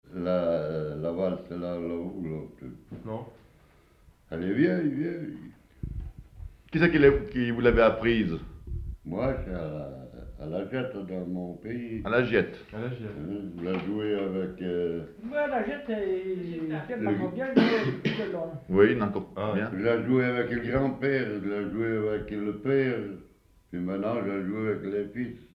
RADdO - Commentaire - Document n°188653 - Témoignage